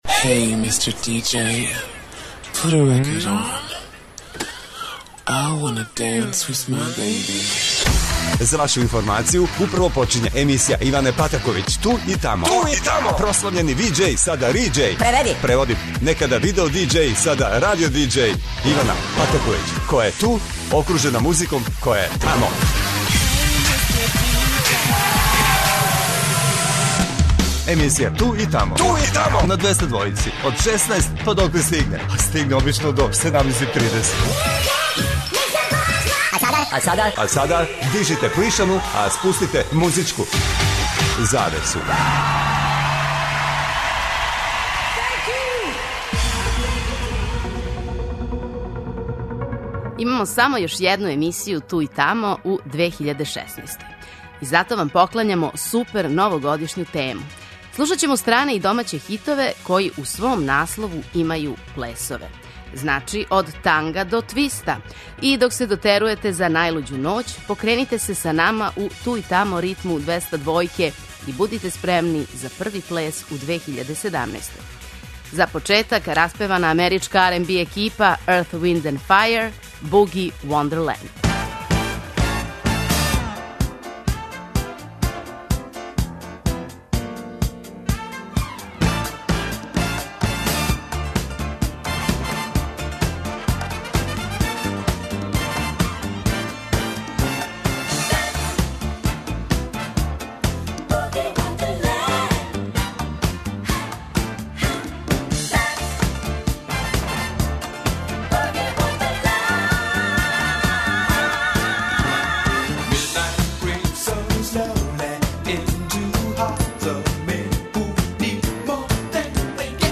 Данашња емисија доноси супер новогодишњу тему! Слушаћемо стране и домаће хитове који у наслову имају плесове.